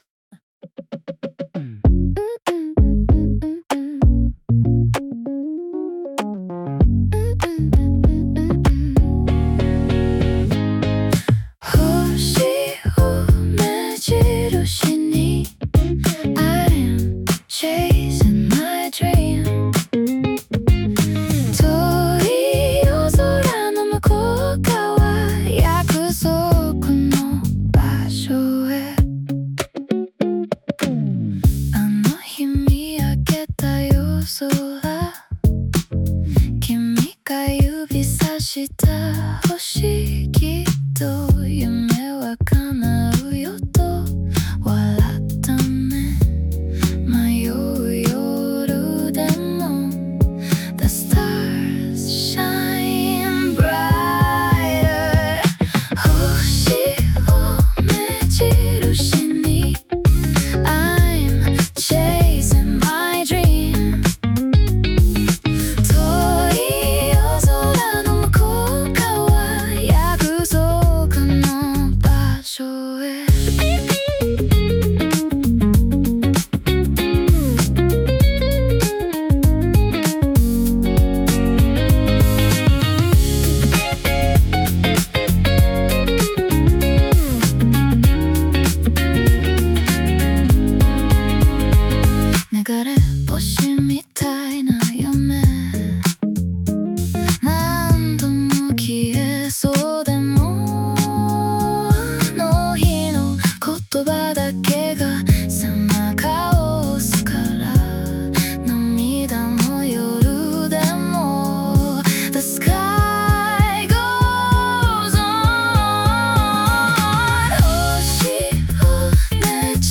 女性ボーカル
イメージ：オルタナティブ,エクスペリメンタル,インディーポップ,シティーポップ,女性ボーカル